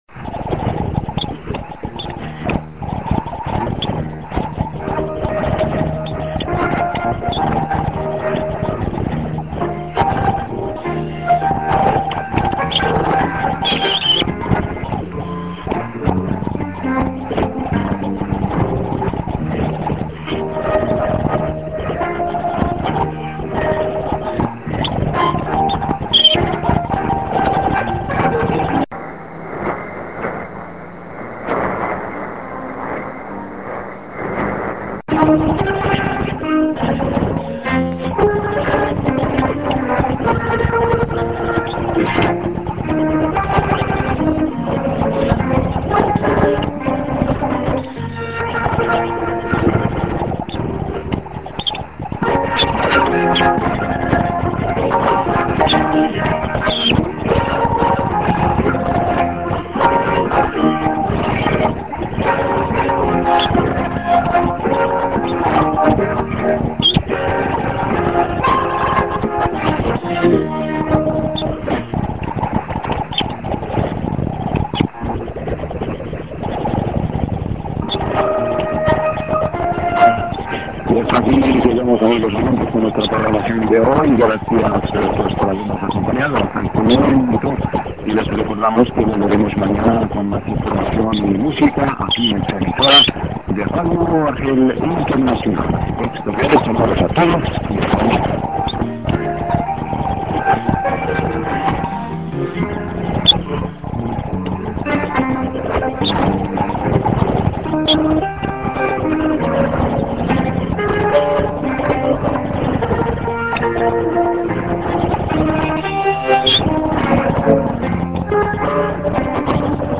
a:　St. music->1'20":ID(man)->music